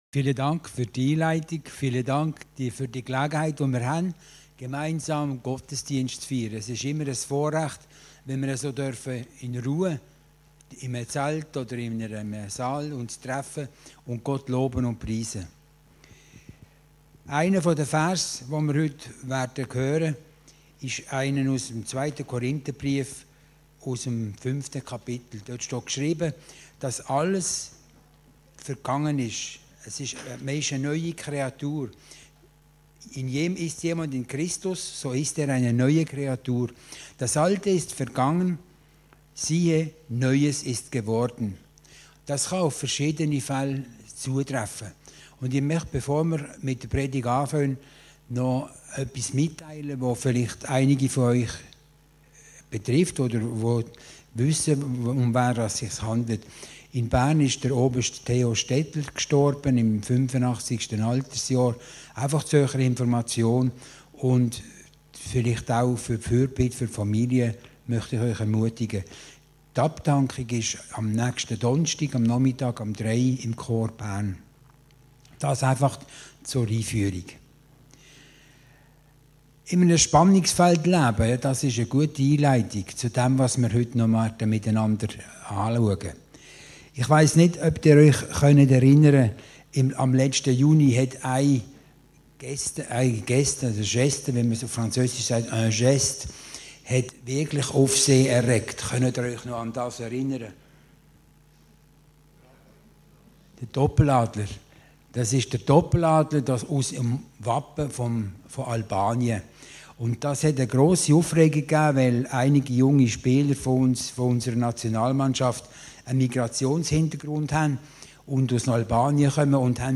Predigten Heilsarmee Aargau Süd – IDENTITÄT